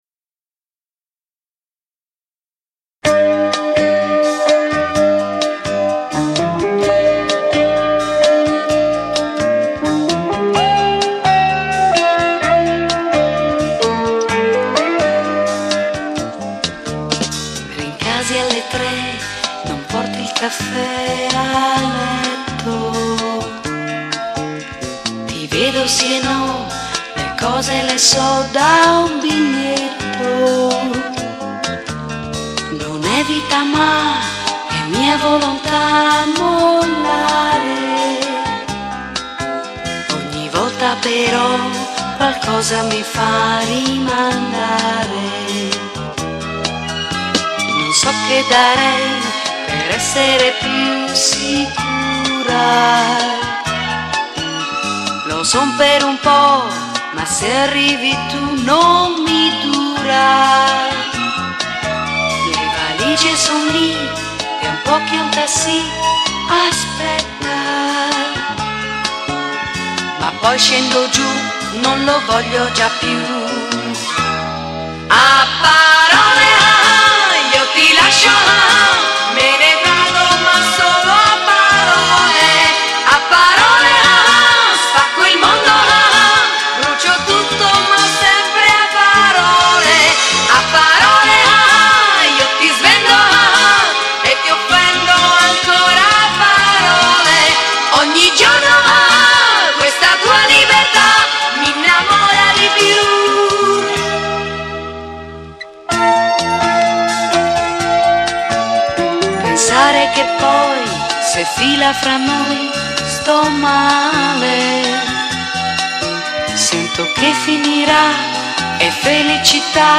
RECORDED & REMIXED AT BUS STUDIOS - ROME